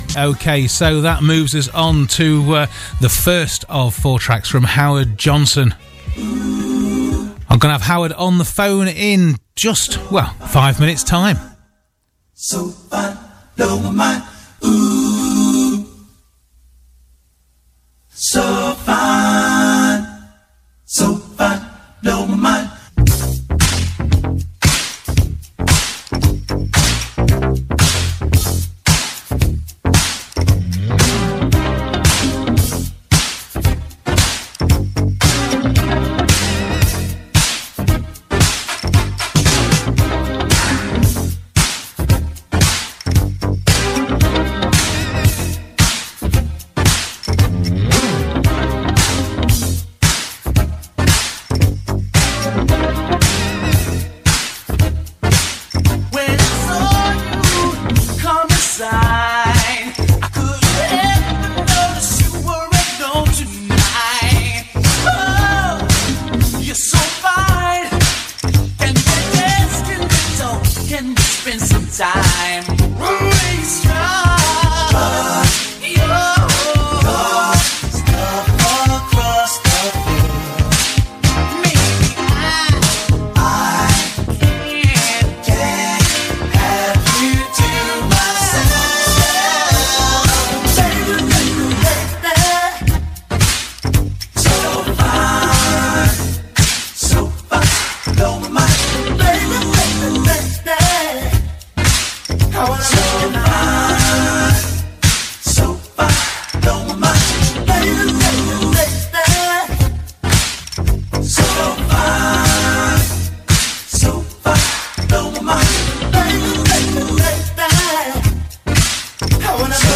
Howard Johnson – 80s solo artist and lead singer with 70s jazz-funk band Niteflyte, Howard chats about his life in music and his forthcoming LP